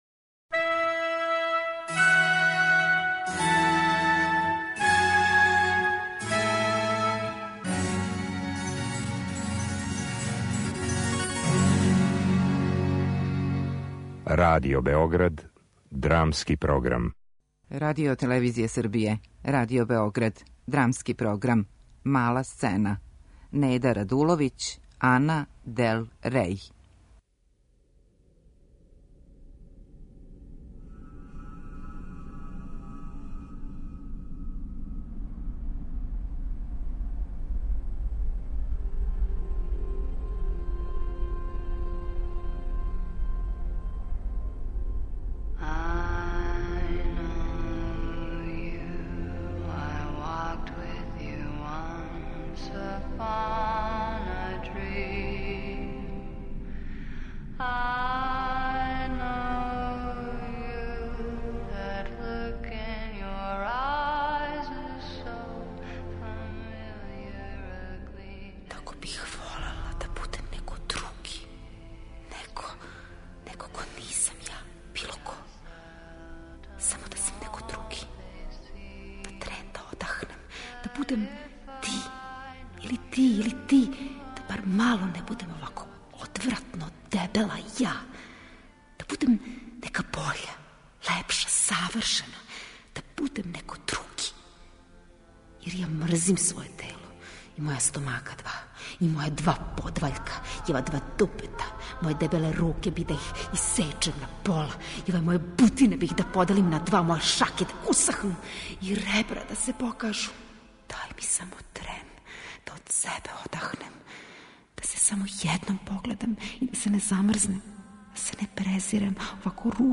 Драмски програм: Мала сцена